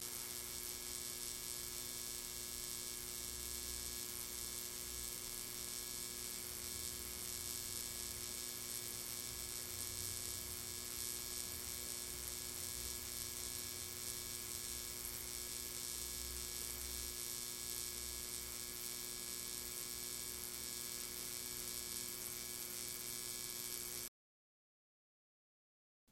Погрузитесь в атмосферу ночного города с подборкой звуков неона: мерцание вывесок, тихий гул ламп, электронные переливы.
Звук зажигания неоновой вывески в кафе или витрине